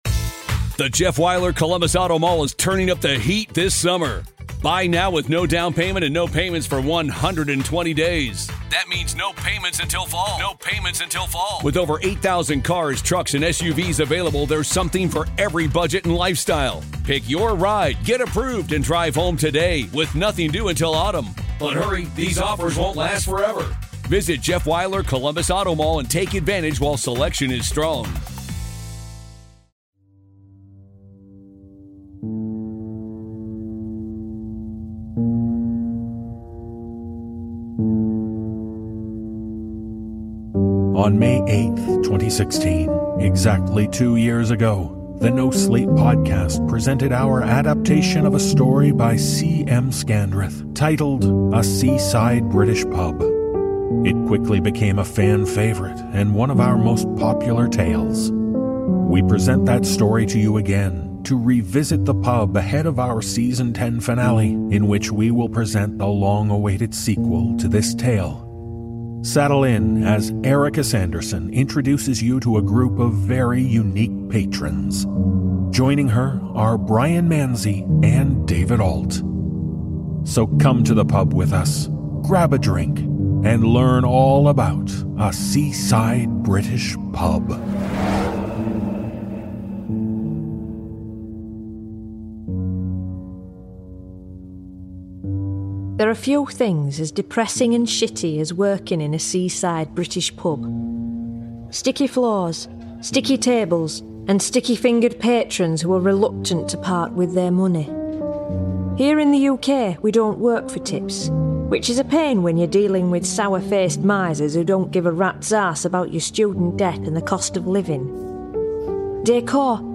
Click here to learn more about the voice actors on The NoSleep Podcast